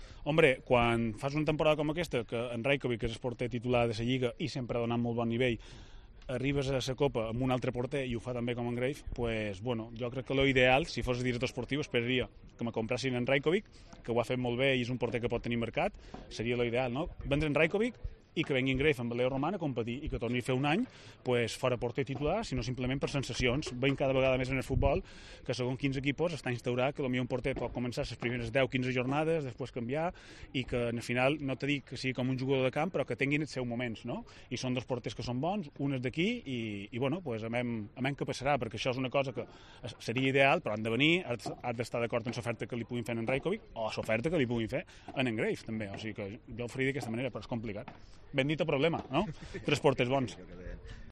El ex portero y ahora comentarista de Movistar, se refiere en Cope a la encrucijada de la portería del RCD Mallorca este verano con Rajkovic, Greif y el regreso de Leo Román